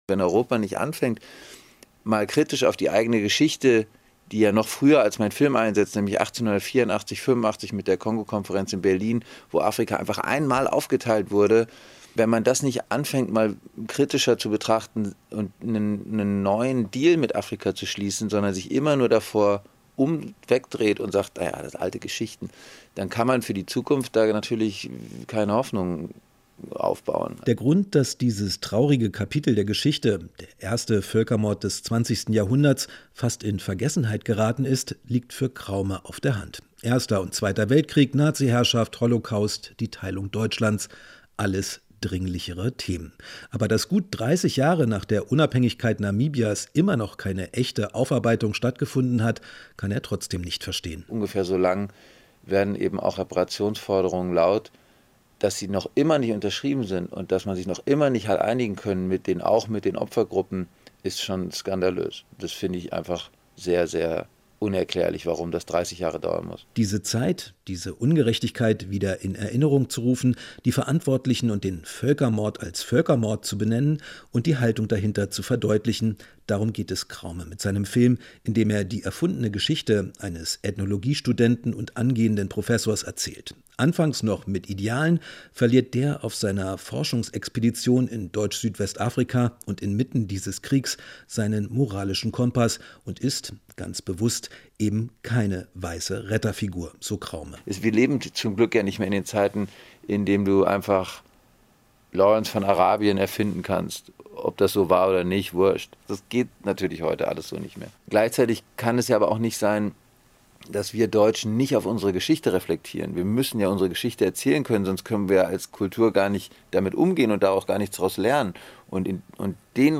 Kinogespräch - Regisseur Lars Kraume: "Man muss davon erzählen"